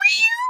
cat_2_meow_04.wav